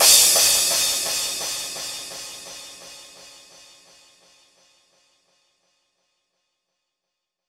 VTDS2 Song Kit 07 Rap Night Club Kings Crash.wav